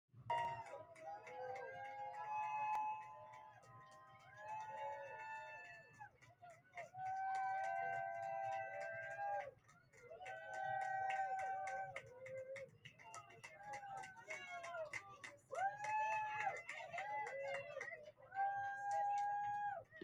Cheers for Marit Stiles Stiles began her speech by discussing the tariffs U.S. President Donald Trump is threatening to impose and what that would mean for the average Ontario resident.